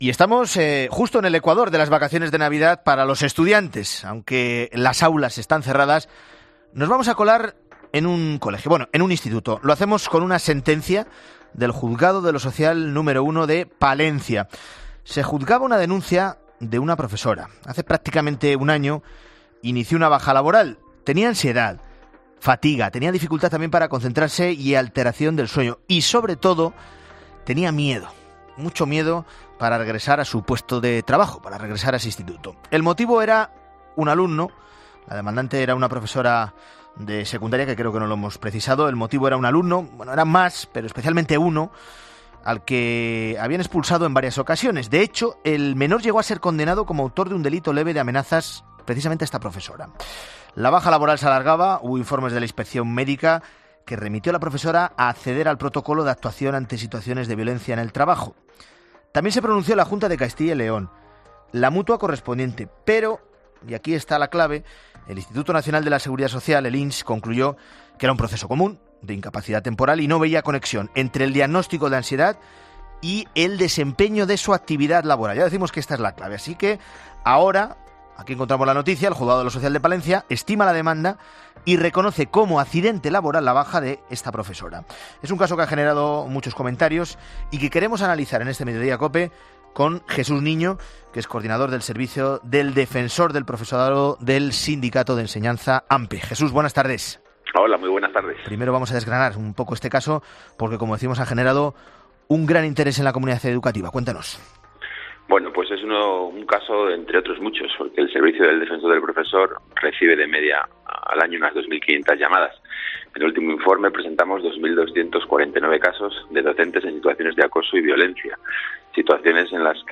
En ‘Mediodía COPE’ hemos hablado con